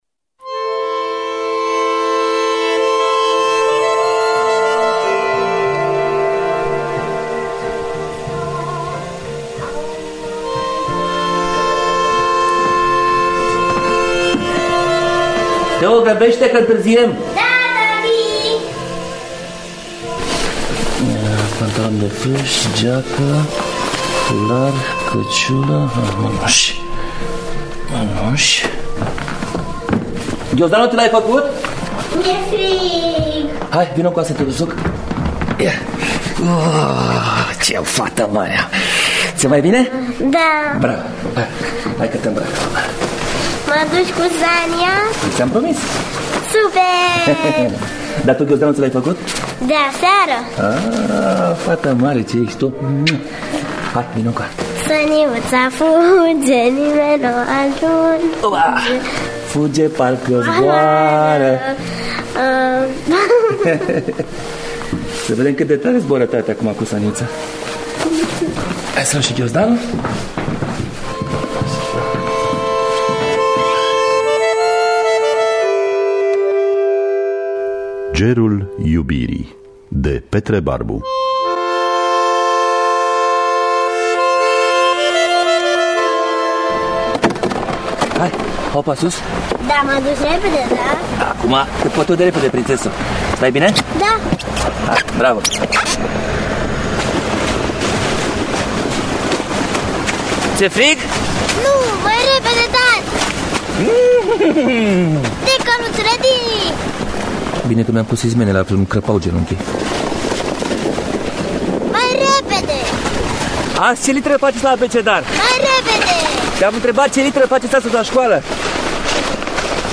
Adaptarea radiofonică